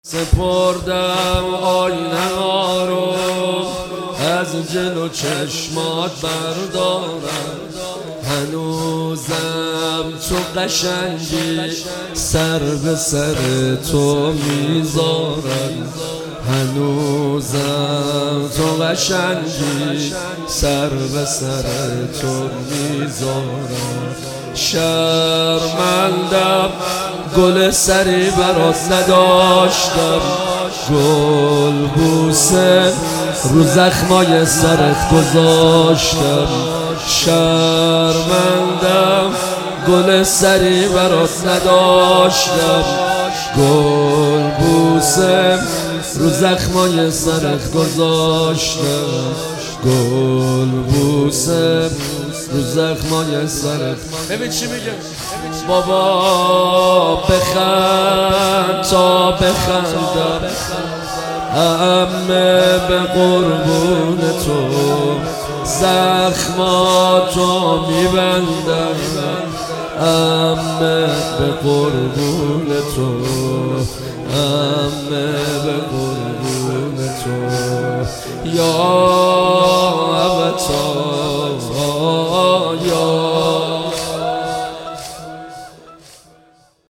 زمینه - شب سوم محرم 1400